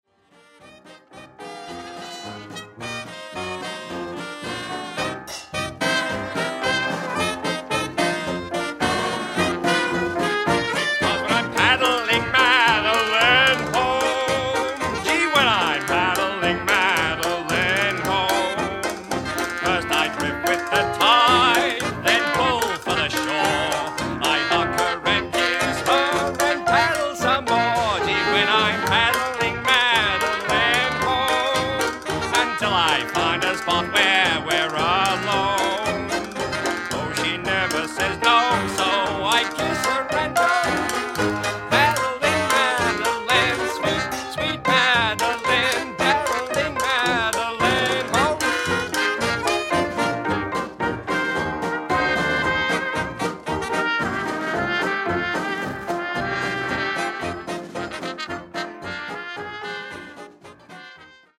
Gatsby Rhythm Kings | Gatsby Era Jazz Band | 1920s Jazz Big Band
Melbourne’s very own 1920’s orchestra.
Gatsby Rhythm Kings Jazz Band are Melbourne's only authentic 1920's Jazz Big Swing band